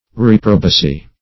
Reprobacy \Rep"ro*ba*cy\ (r?p"r?-b?-c?), n.